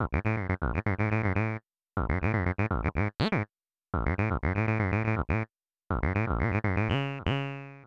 Also below in a faux Ableton-style interface are all the original loops used to create the tracks.
AmajSynthBassTiny.wav